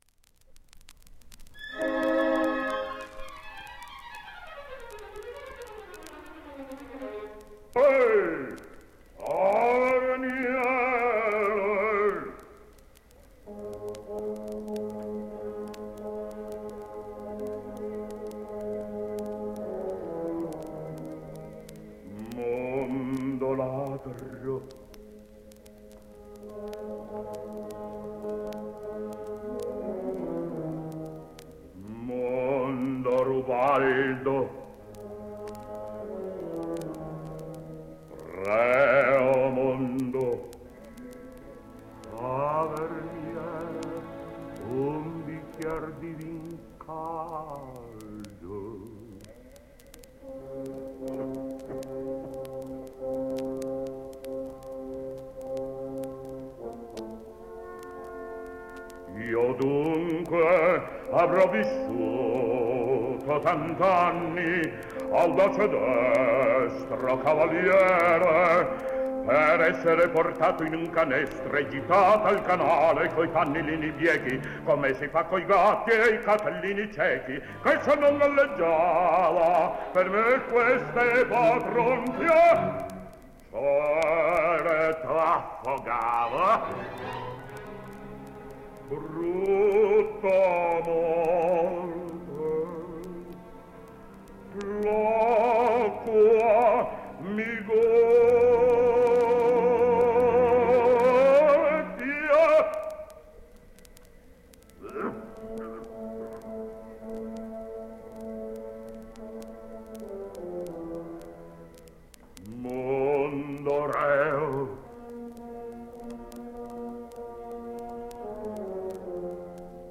basso